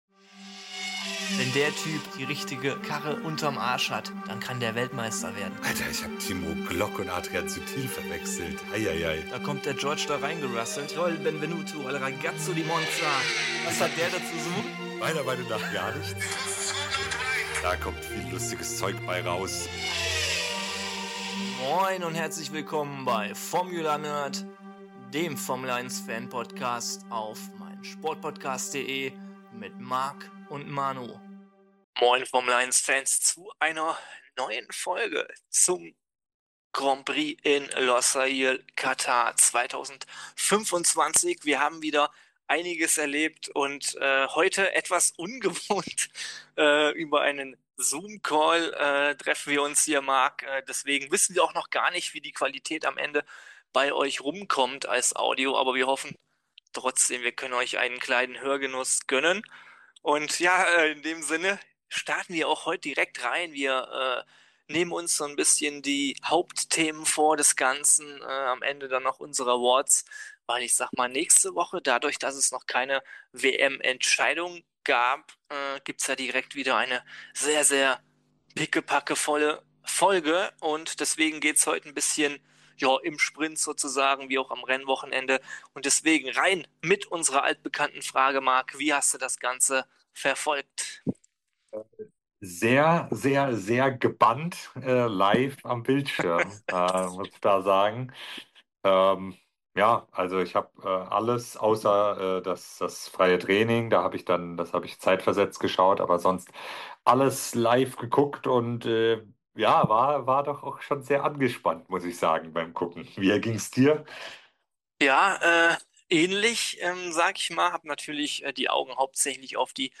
In der heutigen Folge gibt es die Rennanalyse zu Katar 2025. Hinweis in eigener Sache: Die Aufnahmequalität hält nicht unseren sonstigen Standard, da wir ohne die gewohnten Mikrofone und Programme über einen Zoomcall aufnehmen mussten.